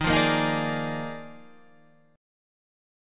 D#M7sus2sus4 Chord